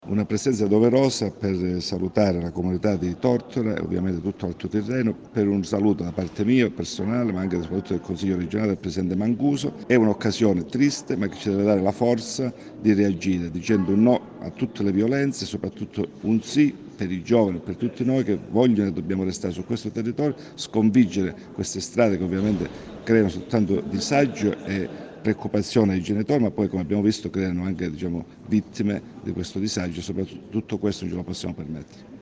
Alla fiaccolata ha partecipato Pietro Molinaro, Consigliere regionale presidente della Commissione anti ‘ndrangheta.
CLICCA E ASCOLTA IL COMMENTO DI PIETRO MOLINARO